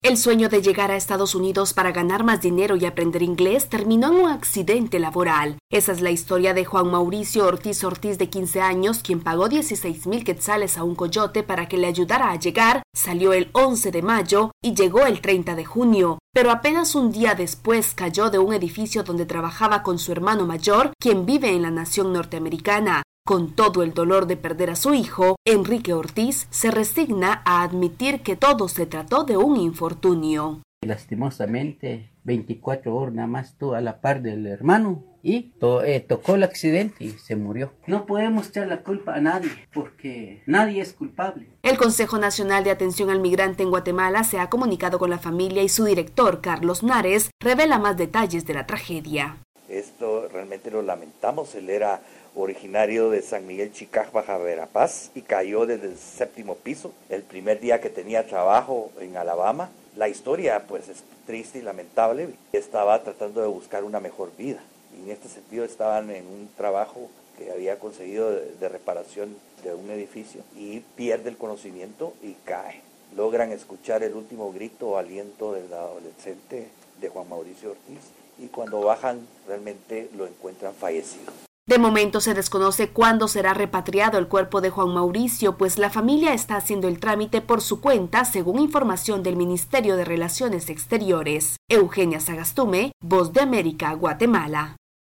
VOA: Informe desde Guatemala